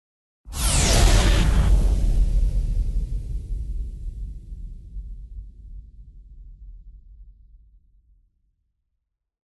Здесь вы найдете эффекты телепортации, хронопрыжков, искривления пространства-времени и других фантастических явлений.
Звук: герой переносится в прошлое